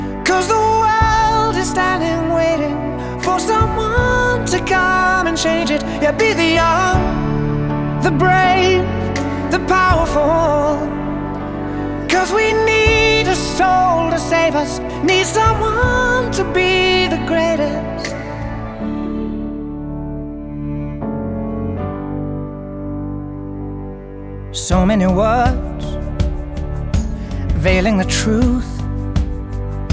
• Pop
A piano lead ballad
a moving string section